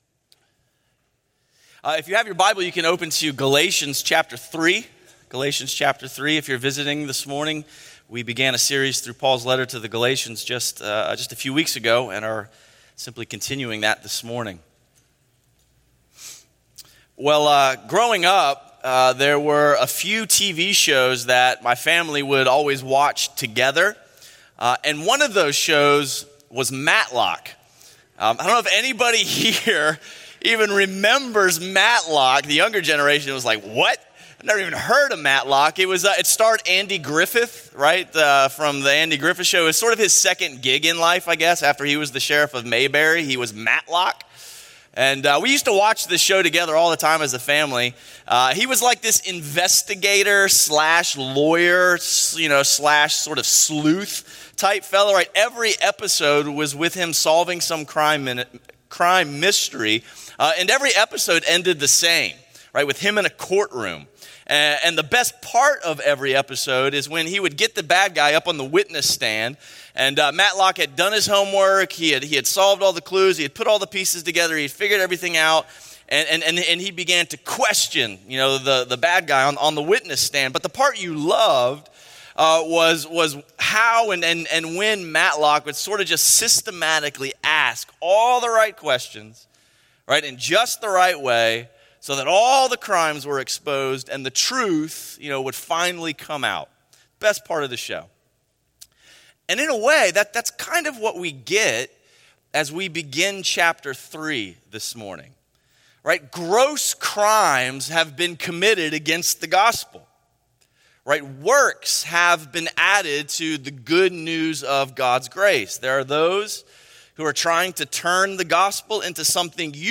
A sermon series on Galatians by Crossway Community Church in Charlotte, NC.